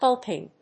/ˈhʌlkɪŋ(米国英語)/